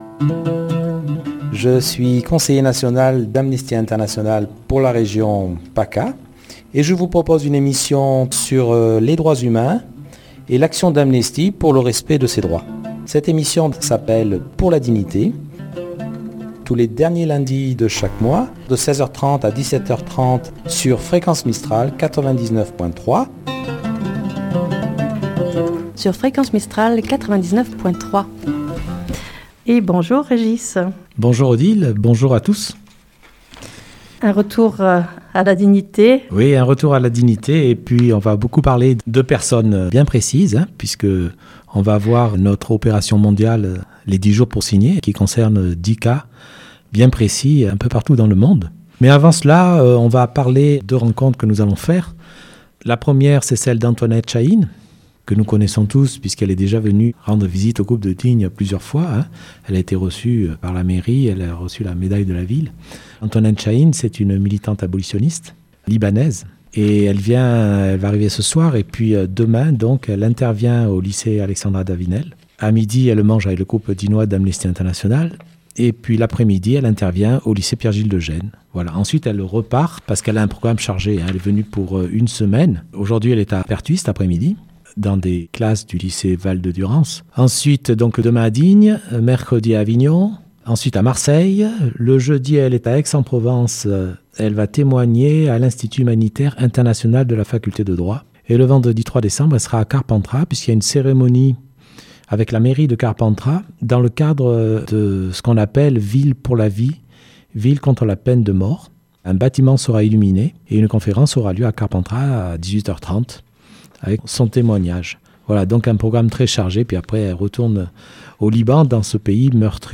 Chanson libanaise